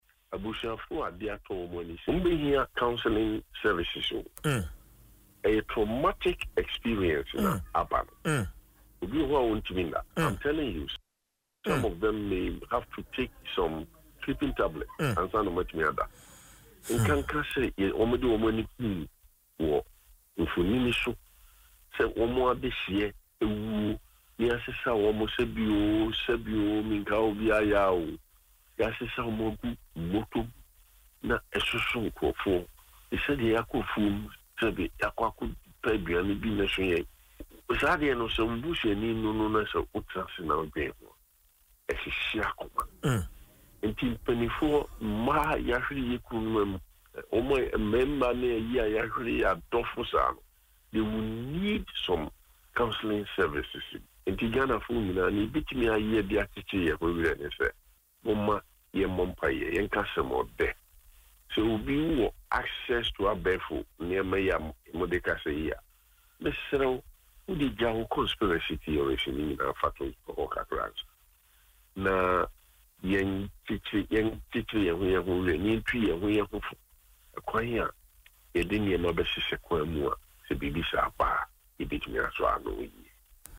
Speaking in an interview on Adom FM’s morning show Dwaso Nsem, Prof. Asante described the experience for the families as deeply traumatic.